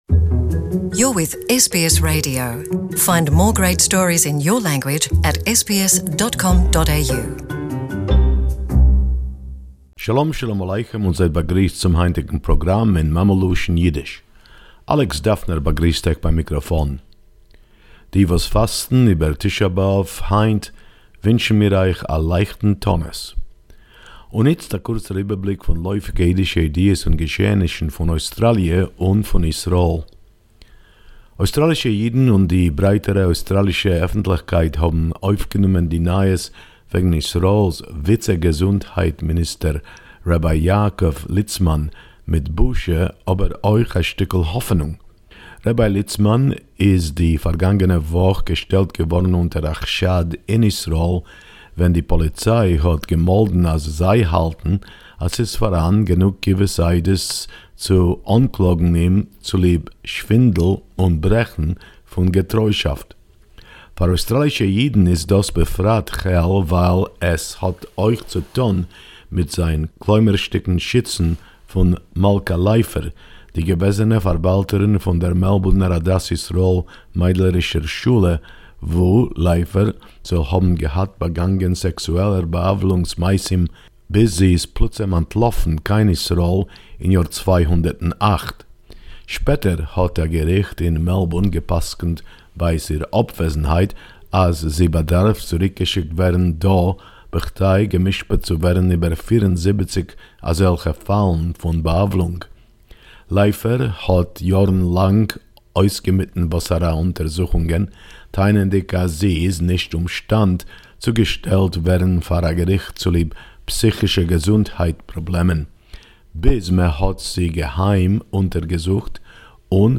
Yiddish News